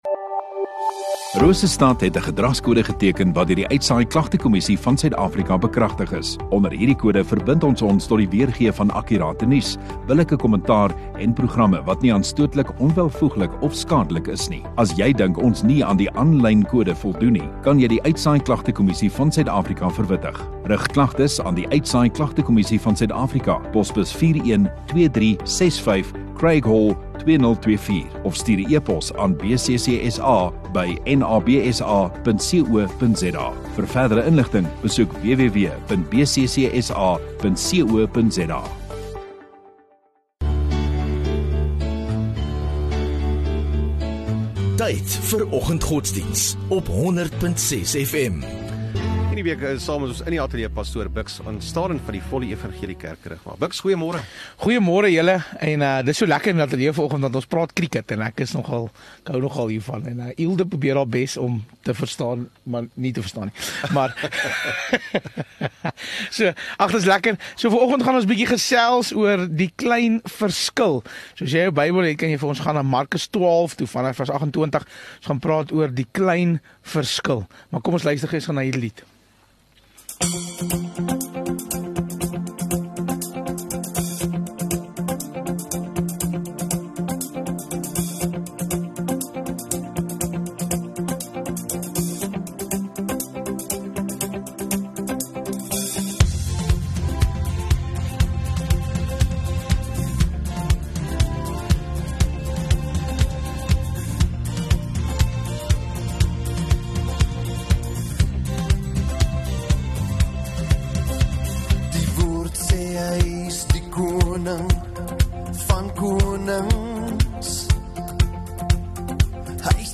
10 Oct Donderdag Oggenddiens